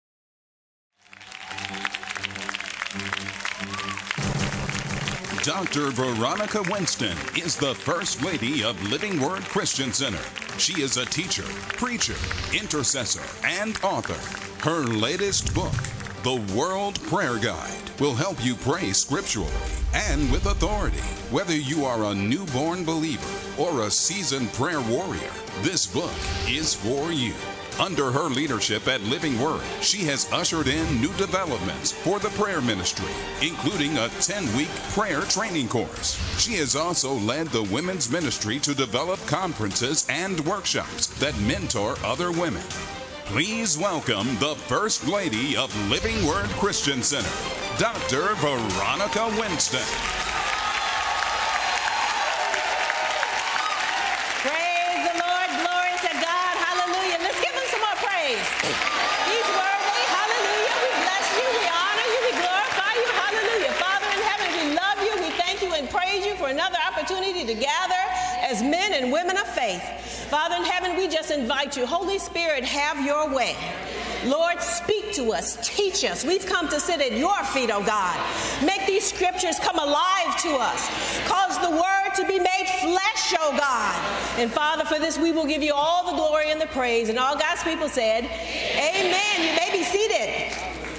This set includes evening and new daytime sessions of powerful prophetic teaching on healing, finances, family, business, ministry, and more.